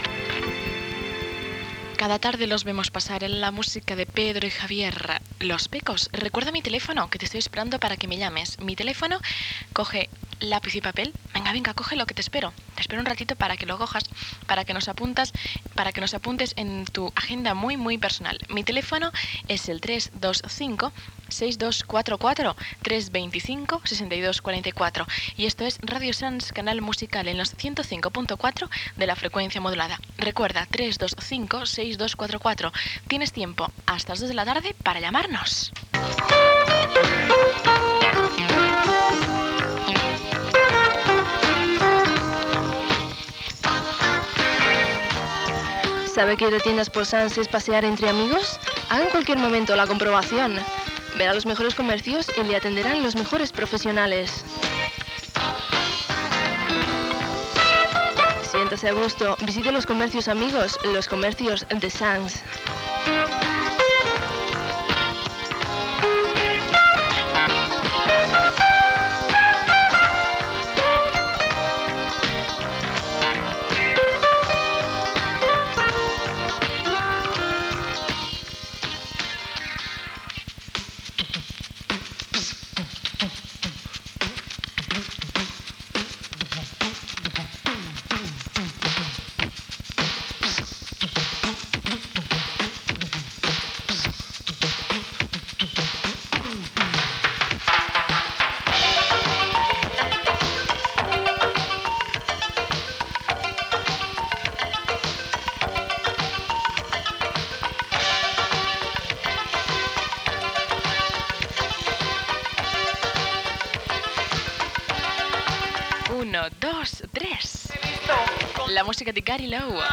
Tema musical, telèfon, identificació, publicitat i tema musical.
FM